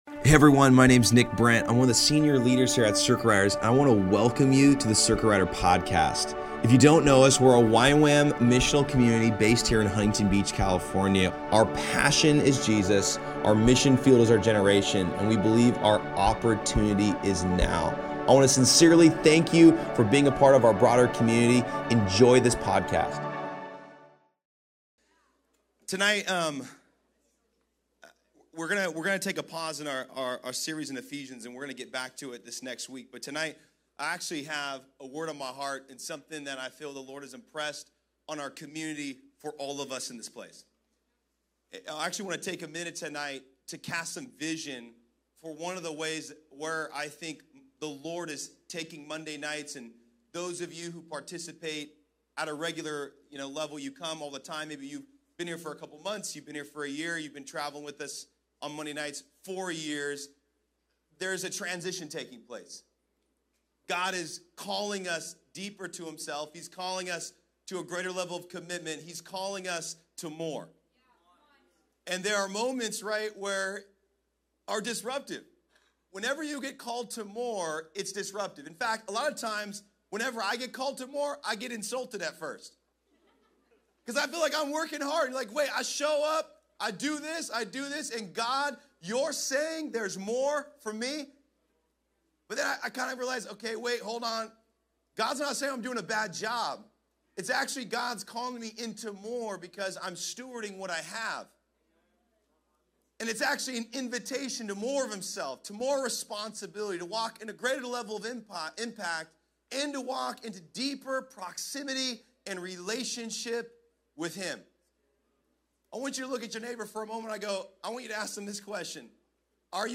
Message
Preaching